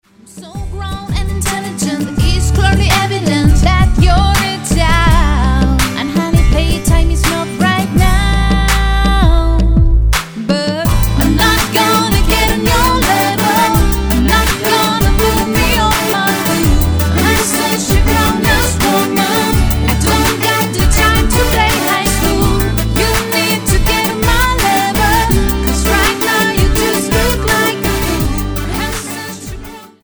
NOTE: Vocal Tracks 10 Thru 18